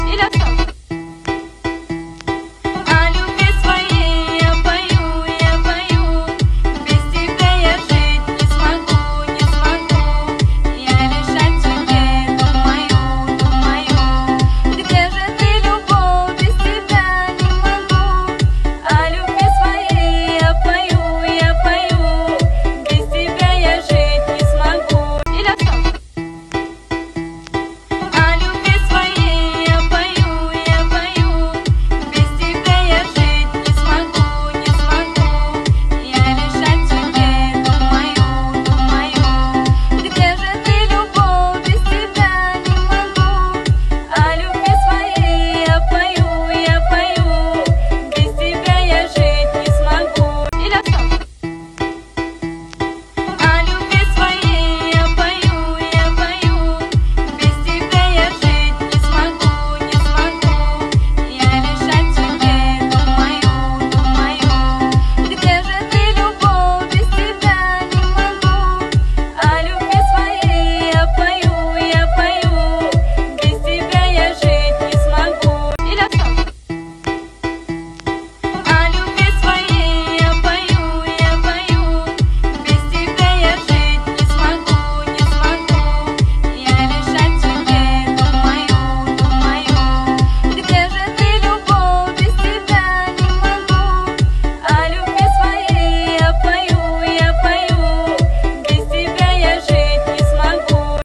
• Качество: 320 kbps, Stereo
женская версия